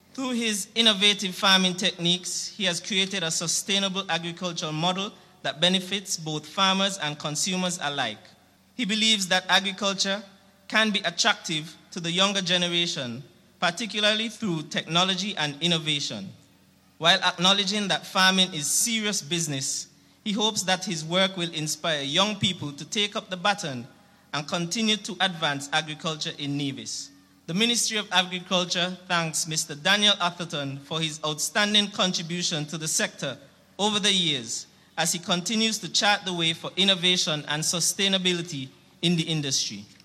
An opening ceremony was held on Thursday.